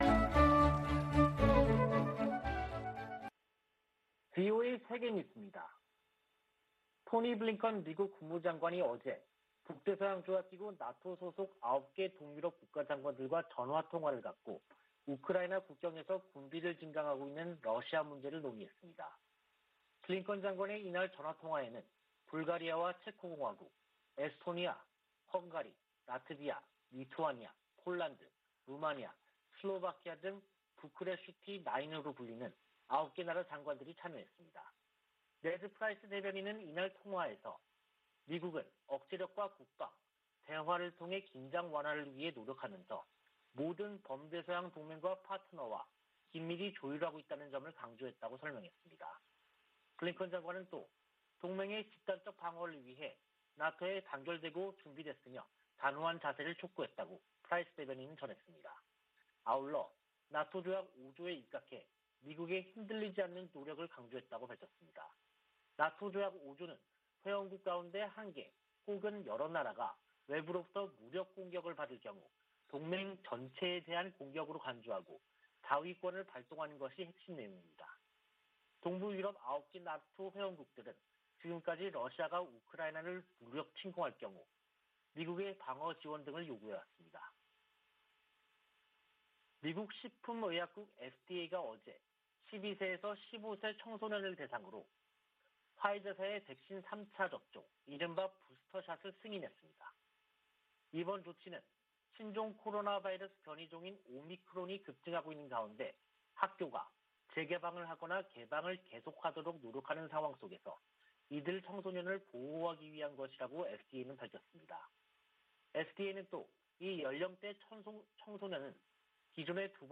VOA 한국어 간판 뉴스 프로그램 '뉴스 투데이', 2022년 1월 4일 3부 방송입니다. 미 국무부 동아시아태평양국이 조 바이든 행정부 들어 대북 외교에서 역할을 복원 중이라는 감사보고서가 나왔습니다. 미국 정부가 북한에 대화 복귀를 거듭 촉구했습니다. 주요 핵무기 보유국들이 핵무기 사용에 반대하고, 핵확산금지조약(NPT)의 의무를 강조하는 공동성명을 발표했습니다.